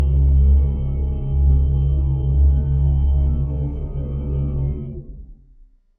Horror Drone
A low, evolving horror drone with dissonant harmonics and subtle pulsing tension
horror-drone.mp3